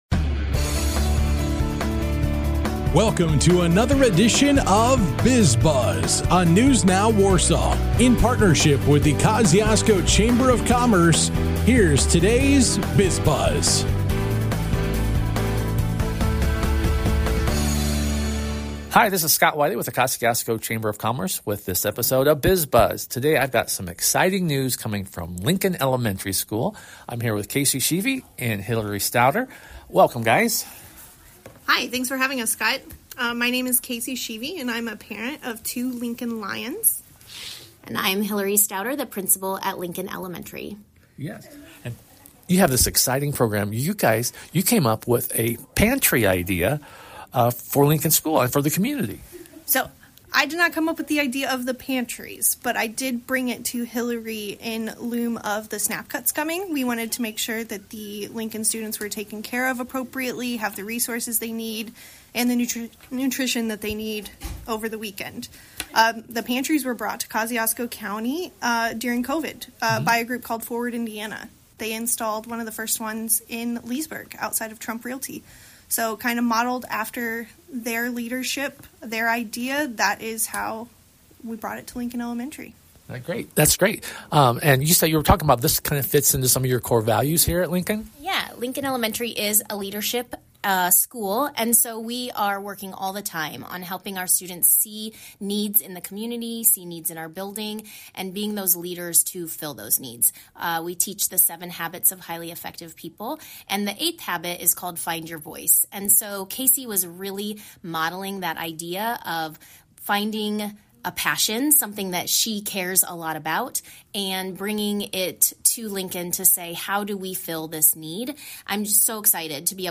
This week, a chat with Lincoln Elementary and Reinholts Furniture and Mattress.